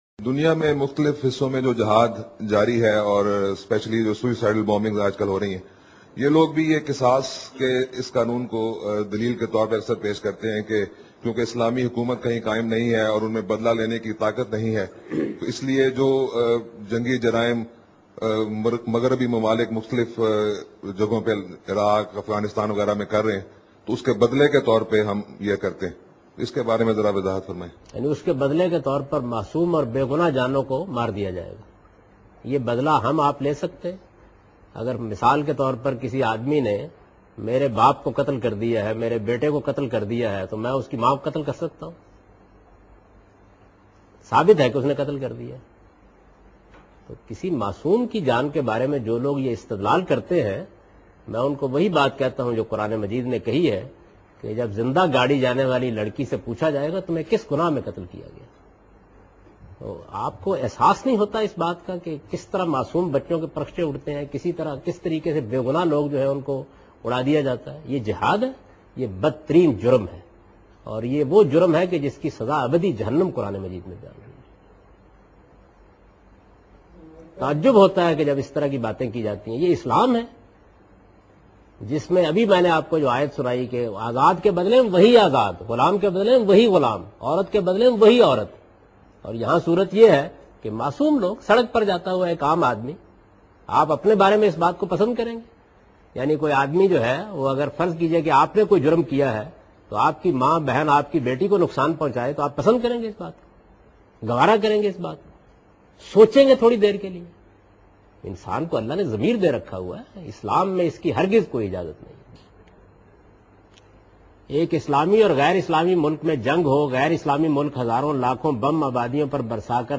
Category: Reflections / Questions_Answers /
اس پروگرام میں جاویداحمد غامدی خودکش حملہ کے بارے میں گفتگو کر رہے ہیں